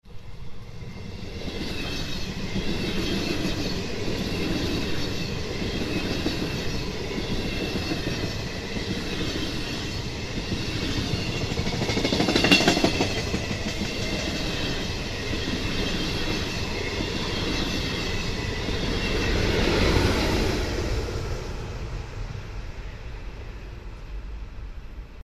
звук поезда